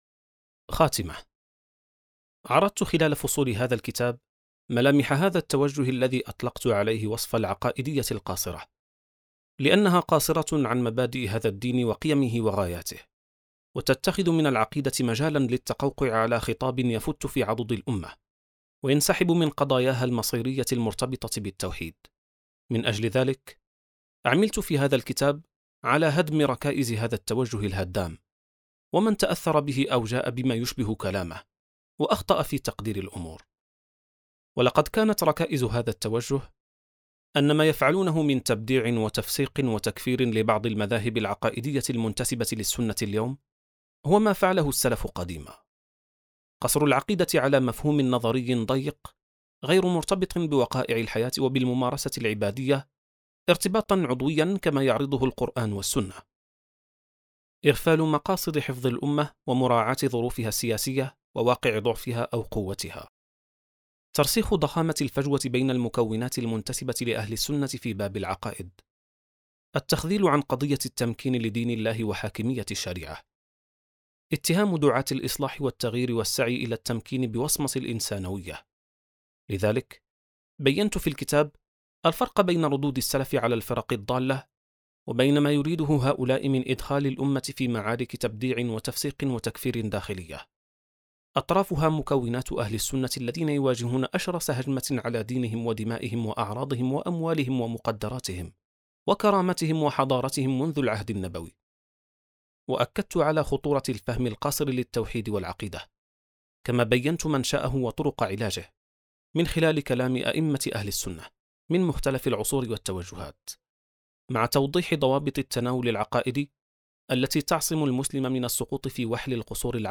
كتاب صوتي | العقائدية القاصرة (88): خاتمة • السبيل